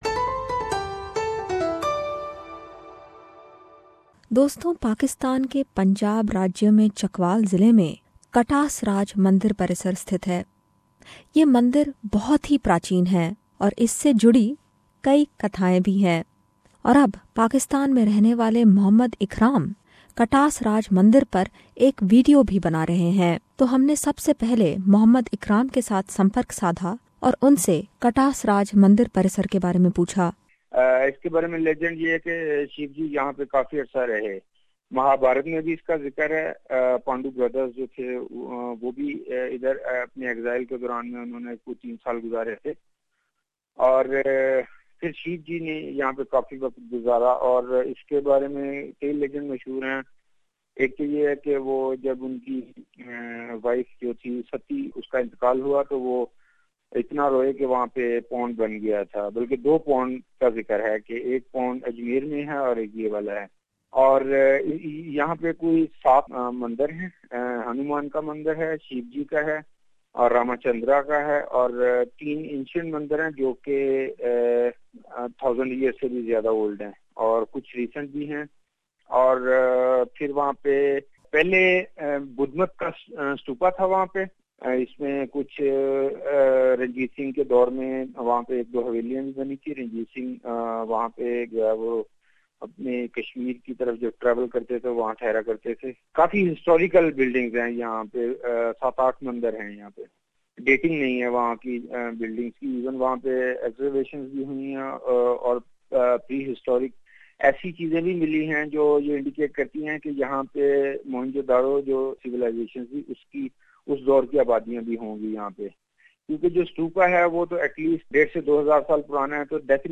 Tune in for this very special report..
We also spoke to an Indian who moved to India from Pakistan after partition but tells us about importance of this ancient temple.